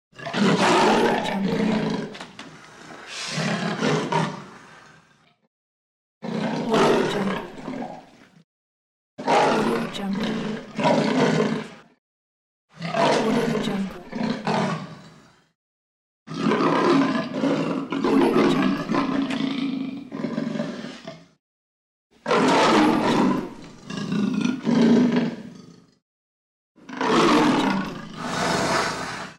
Lion Roaring Efeito Sonoro: Soundboard Botão
Lion Roaring Botão de Som